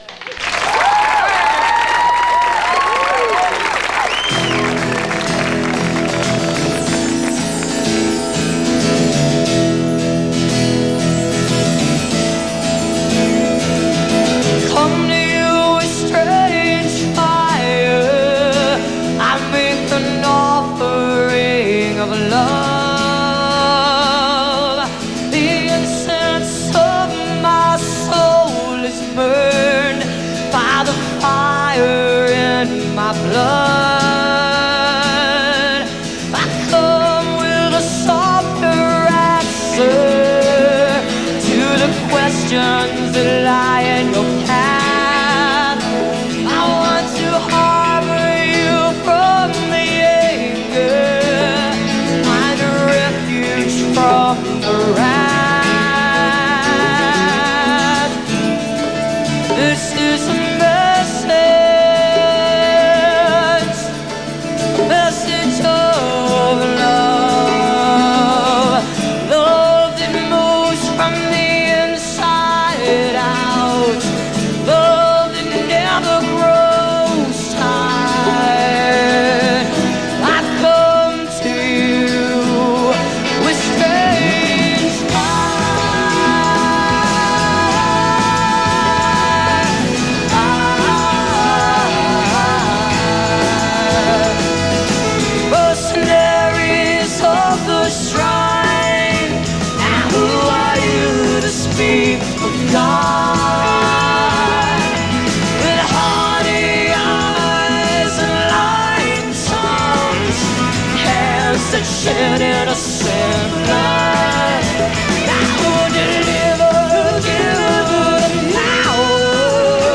(audio capture from a video)